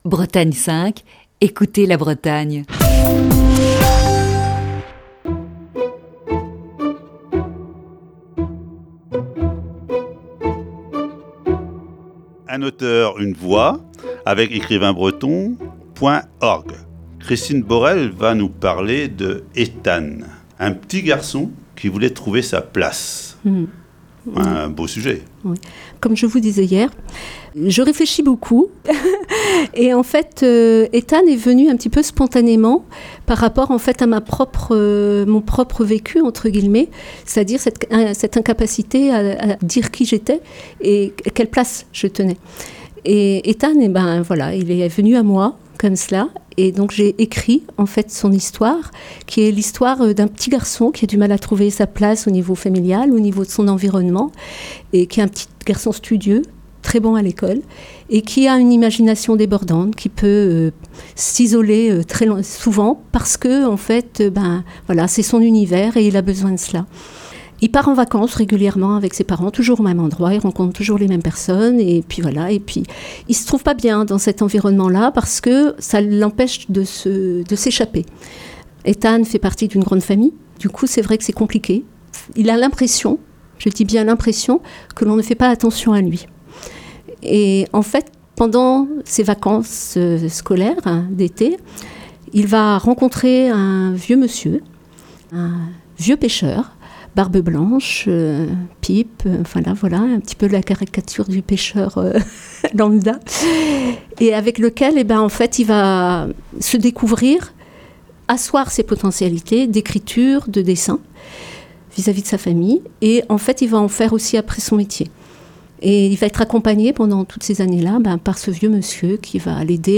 Ce jeudi, voici la quatrième partie de cet entretien.